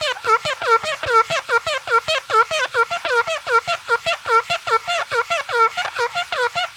cartoon_squeaky_cleaning_loop_05.wav